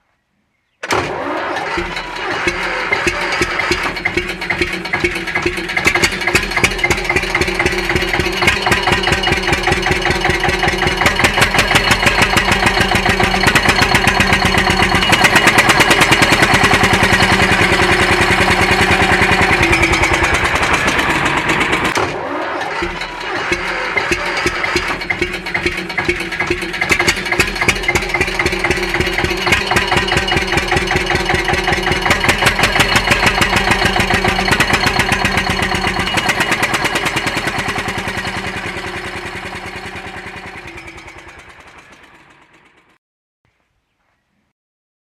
Eicher Traktor Klingelton
Beschreibung: Der Klingelton „Eicher Traktor EKL 15/2“ mit 16 PS aus dem Jahr 1954 bringt den einzigartigen Sound eines echten Eicher-Traktors direkt auf dein Smartphone! Hol dir jetzt den kostenlosen Klingelton im MP3-Format und genieße das typische Motorgeräusch, das für viele Landwirte und Technikbegeisterte nostalgische Gefühle weckt.
eicher-traktor-klingelton-de-www_tiengdong_com.mp3